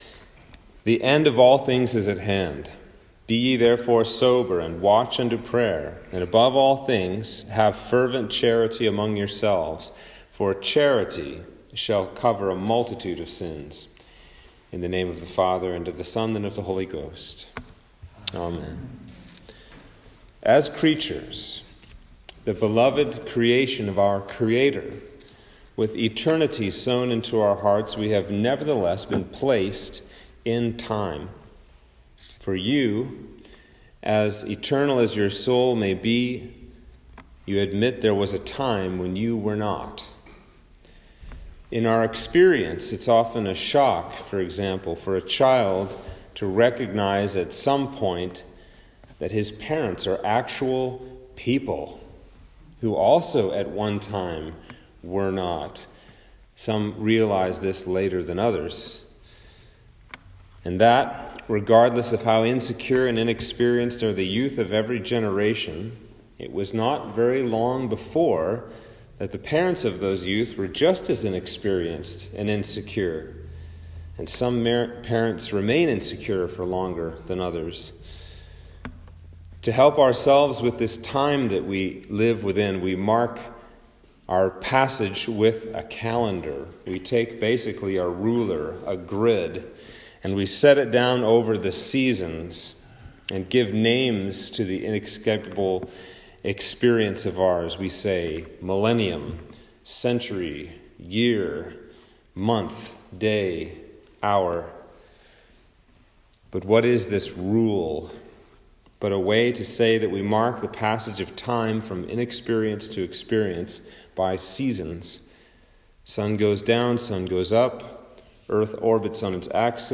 Sermon for Sunday After Ascension
Sermon-for-the-Sunday-After-Ascension-2021.m4a